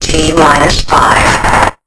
It's indeed a strange format and result has crap click noise